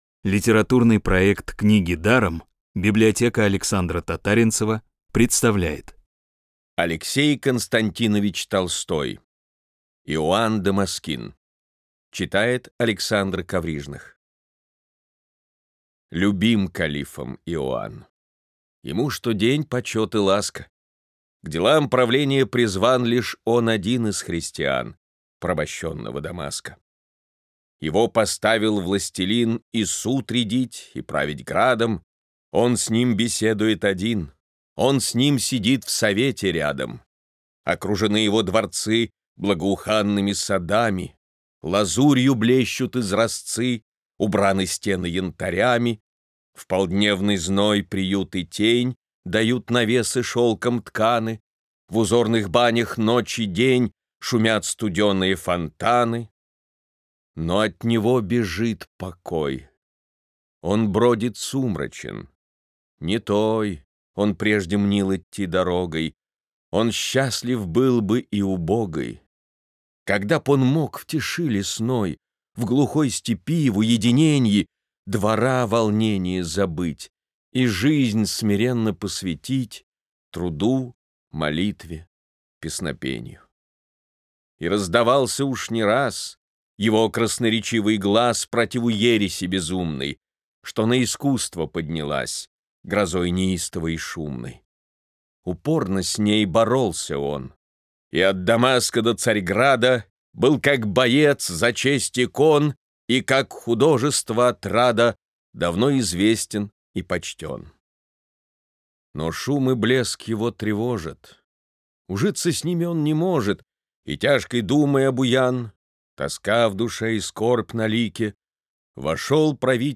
Бесплатная аудиокнига «Иоанн Дамаскин» от Рексквер.
«Книги даром» подготовили для вас аудиоверсию поэмы «Иоанн Дамаскин».